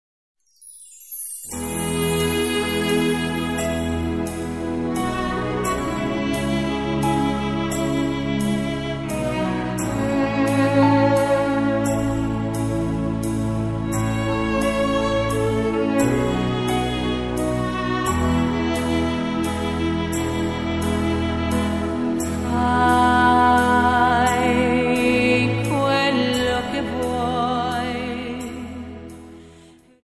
Waltz 29 Song